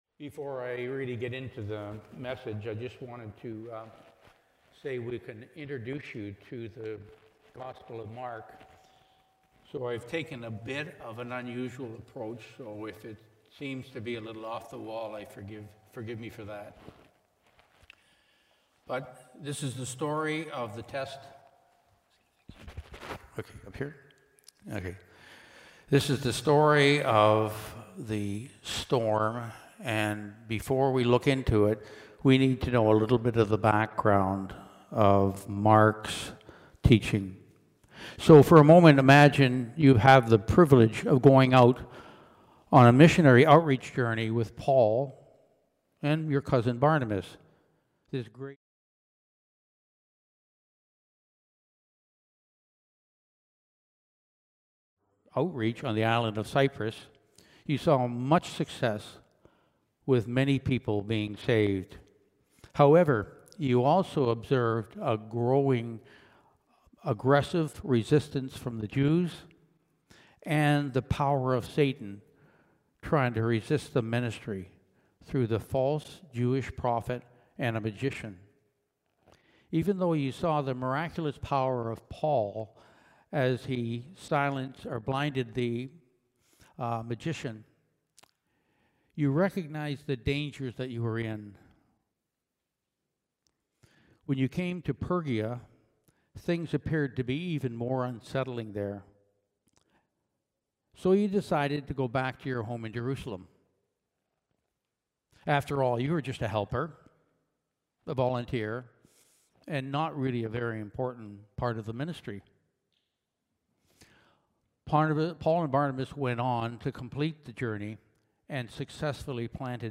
BACK TO SERMON LIST Preacher